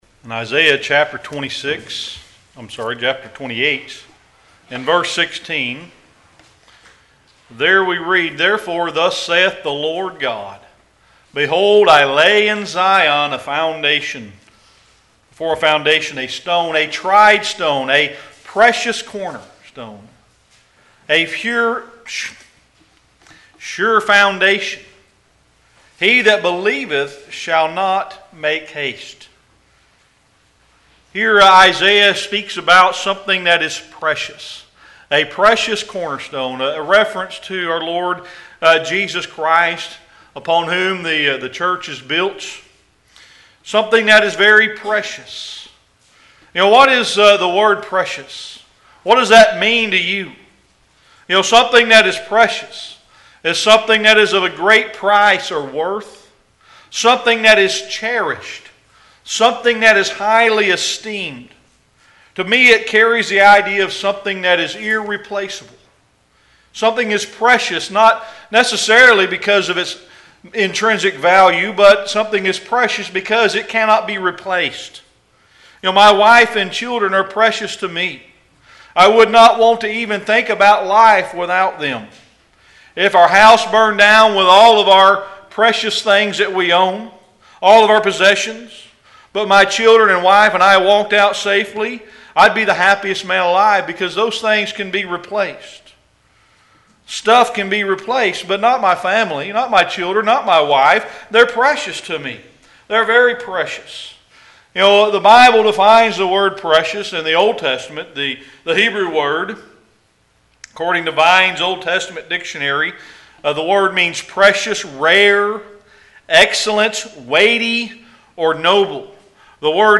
Series: Sermon Archives
1 Peter 2:7-8 Service Type: Sunday Evening Worship What is PRECIOUS to You?